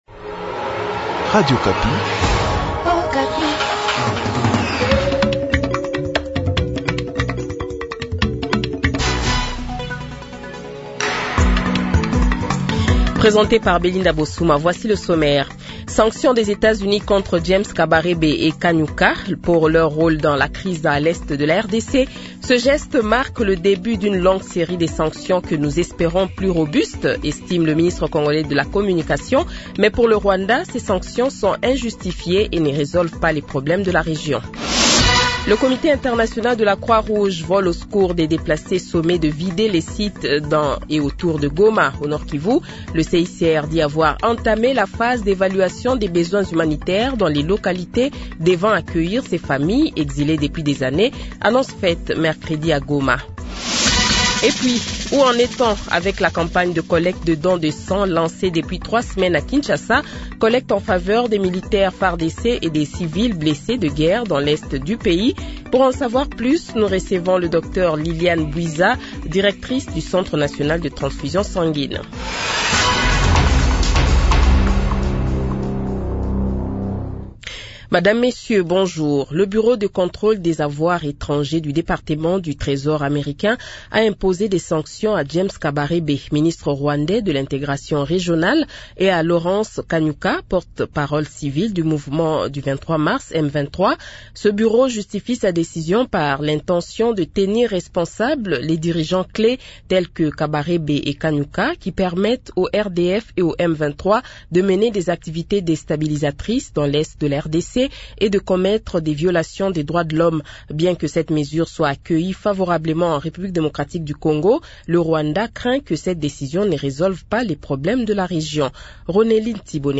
Le Journal de 12h, 21 Fevrier 2025 :